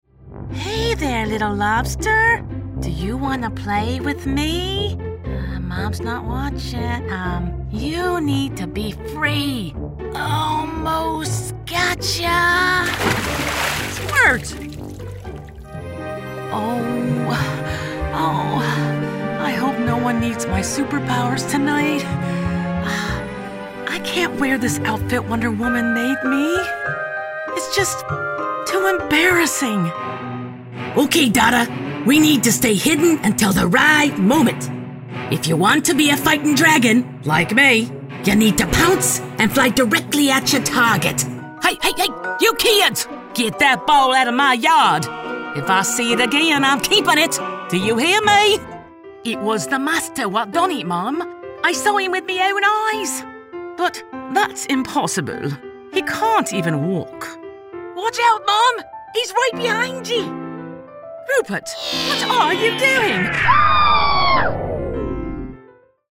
Female Voice Over, Dan Wachs Talent Agency.
Mature, Attention-Grabber, Conversational